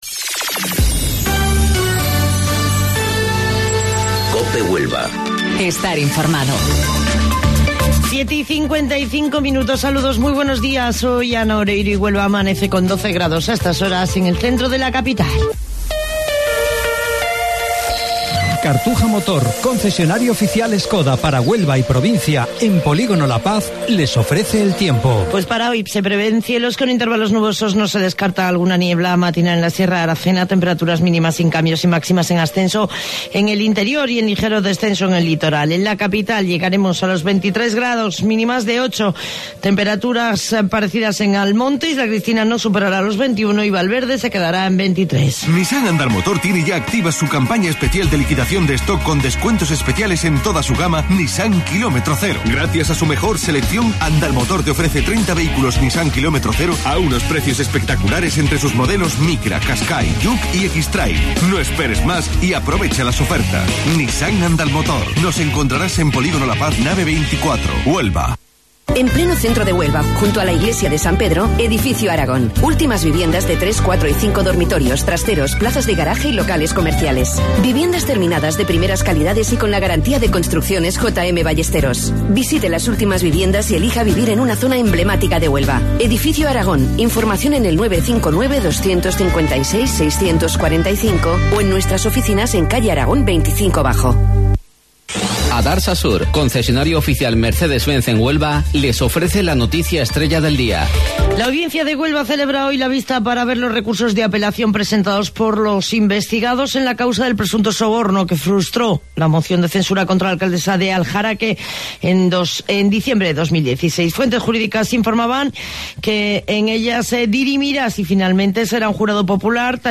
AUDIO: Informativo Local 07:55 del 18 de Marzo